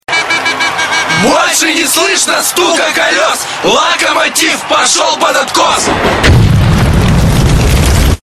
Список файлов рубрики Футбольные кричалки.